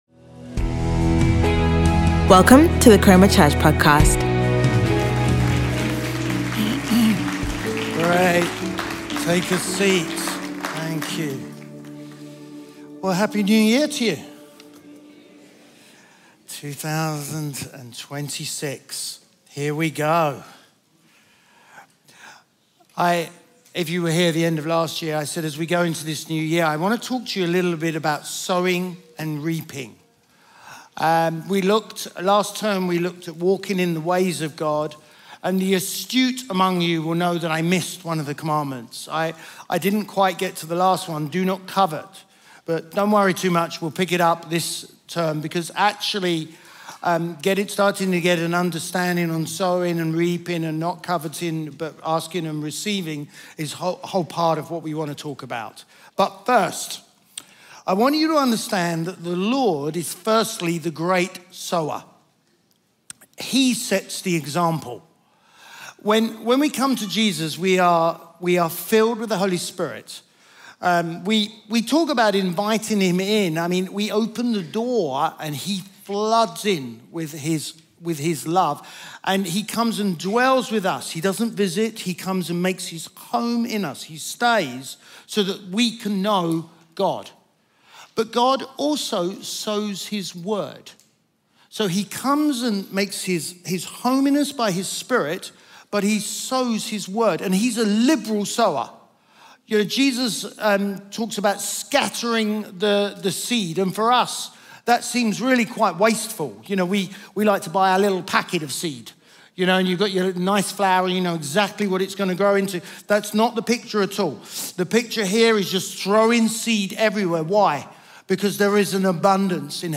Chroma Church Live Stream